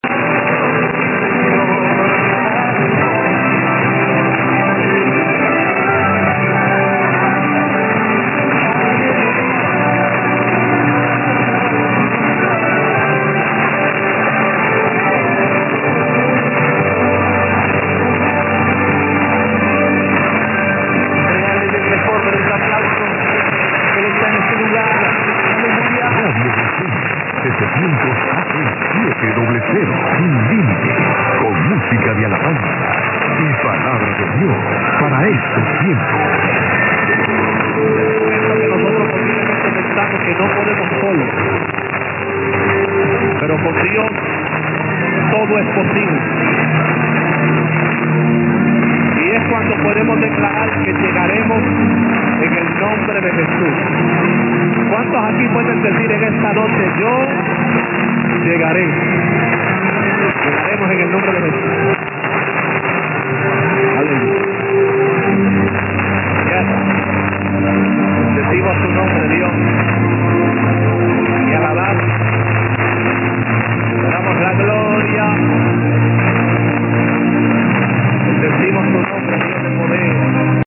Good / dominant at the time on a channel that typically has a lot of activity: Colombia, Brazil, WLW, Ecuador, Argentina for starters.